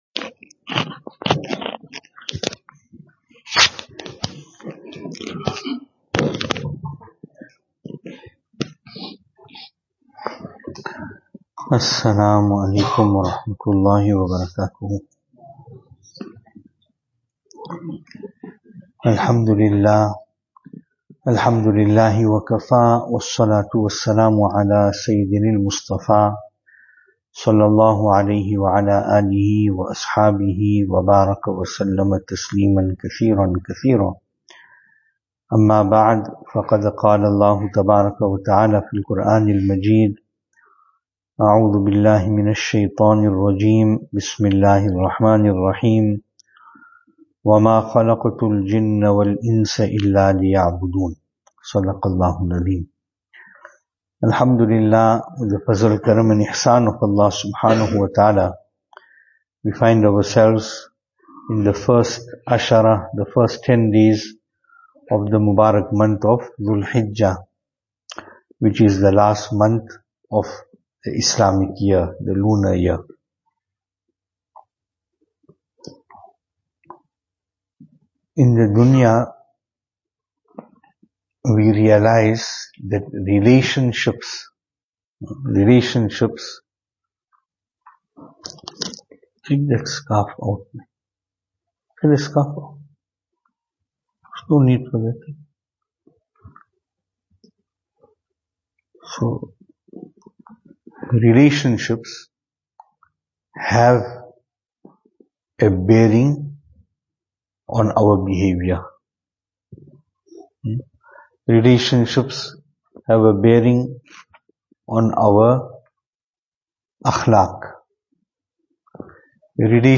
Venue: Albert Falls , Madressa Isha'atul Haq
Service Type: Jumu'ah